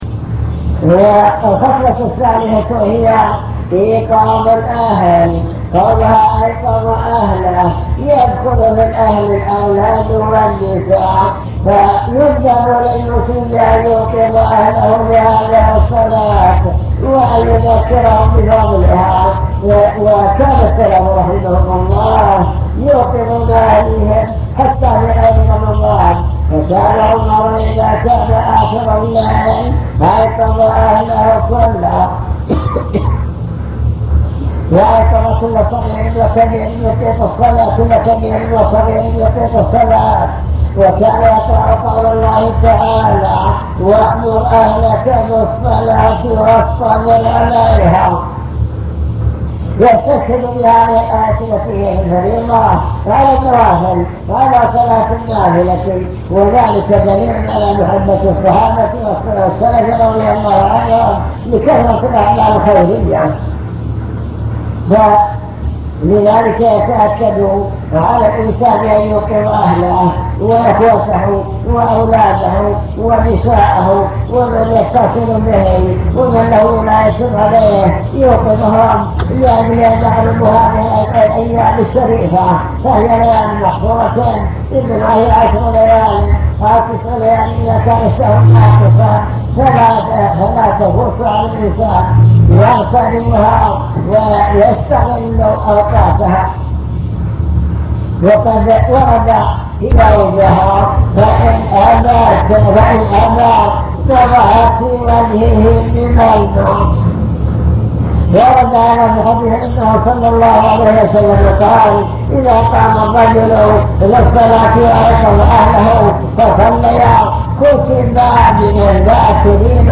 المكتبة الصوتية  تسجيلات - محاضرات ودروس  مجموعة محاضرات ودروس عن رمضان العشر الأواخر من رمضان